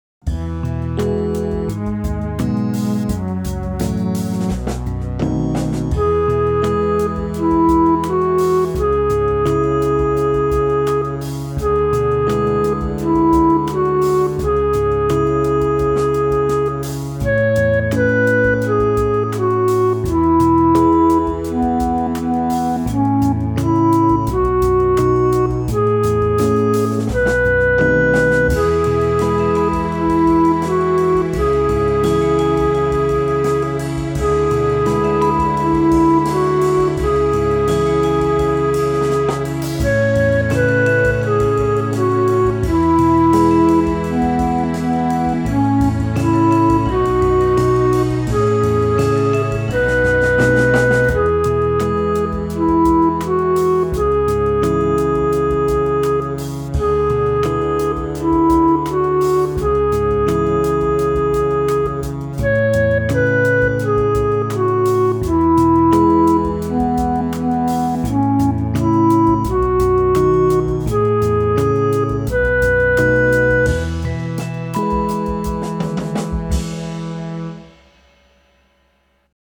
My backing is at 85 bpm